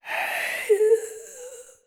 SFX_Mavka_Defeated_01.wav